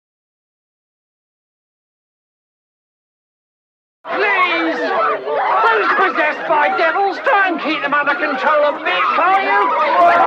I took the original video I had produced, imported the audio into Audacity, and yeah it was SO low... so I AMPLIFIED it by 23 Db, and made it nice and loud, exported the sound as an MP3, took the video, stripped the audio out, combined in the NEW amplified audio and then combined that into another HIGH rate video using OpenShot....